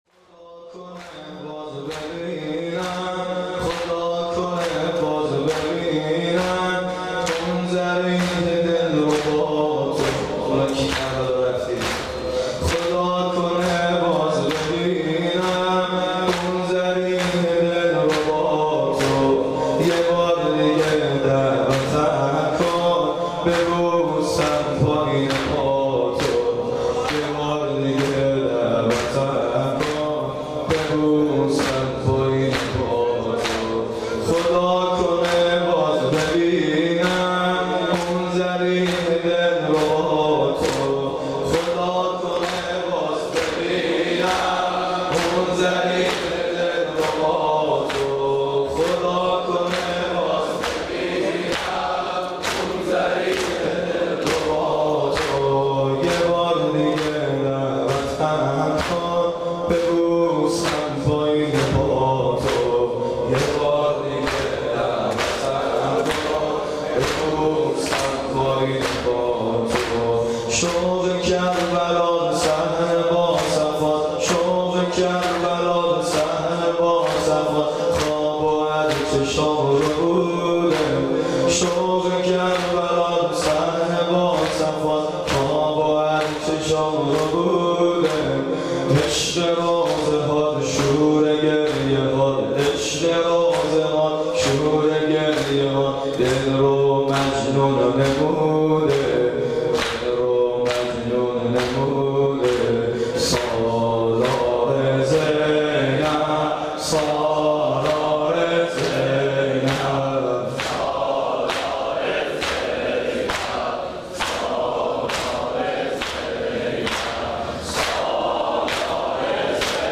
• مداحی